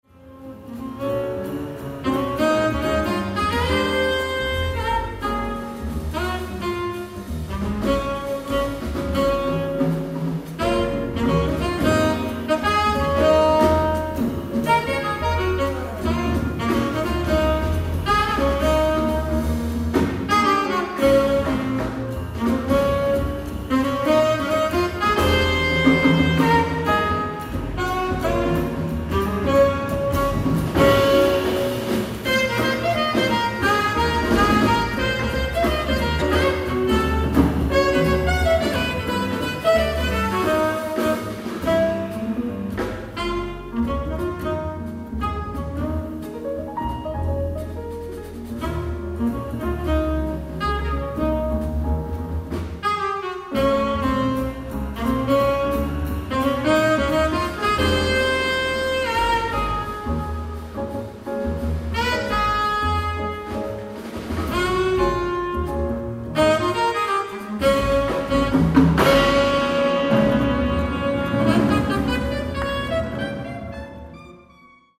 ライブ・アット・ノースリッジ、カリフォルニア